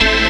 orgTTE54015organ-A.wav